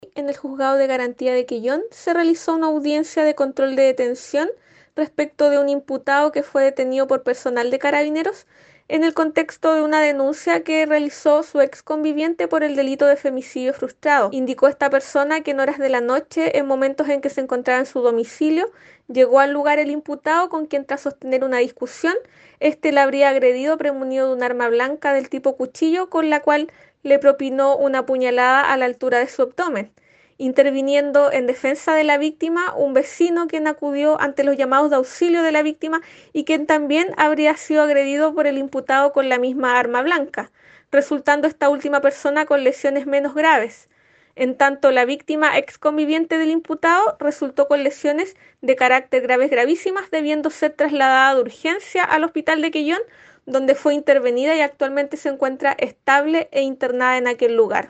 La fiscal (S) Paulina Otero detalló los sucesos acontecidos en la jornada del día miércoles, ocasión en la que tuvo lugar una audiencia de control de la detención respecto de este hombre, quien utilizó un arma blanca para atentar contra la víctima.
La mujer se encuentra internada en el Hospital de Quellón, estable dentro de su gravedad, según informó la fiscalía a través de la fiscal (S) Paulina Otero.